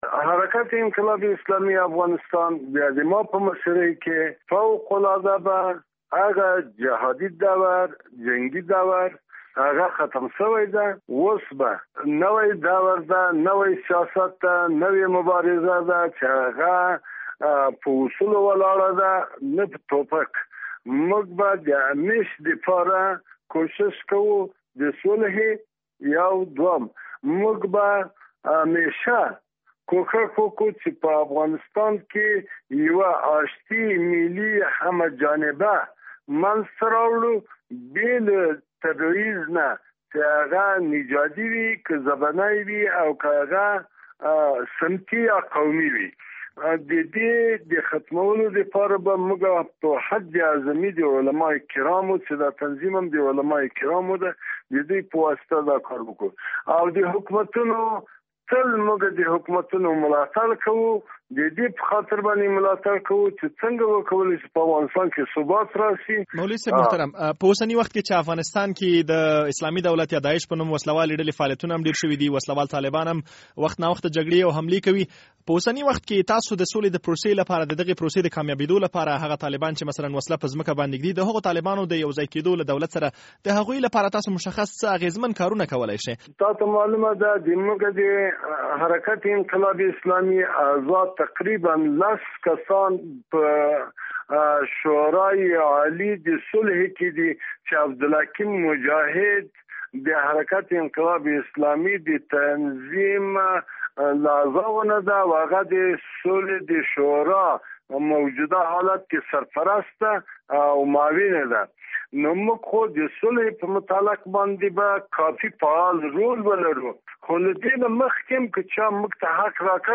له مولوي قلم الدین سره مرکه